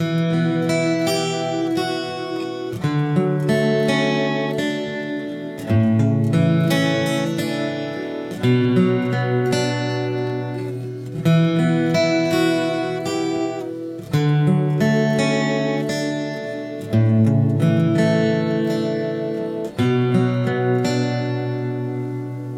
吉他循环播放 " 原声弹奏吉他100bpm C调
描述：免费的原声吉他循环。
Tag: 量化后 常规 循环 吉他